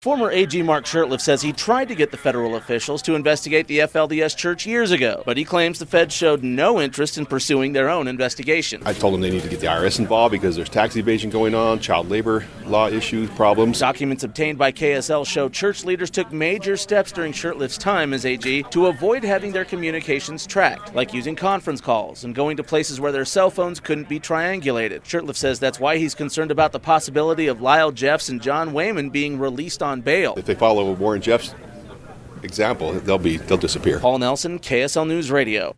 Mark Shurtleff says the raids by federal investigators are long overdue. He claims he tried to get federal investigators to look into the FLDS Church years ago, to no avail. Here's an extended portion of our interview.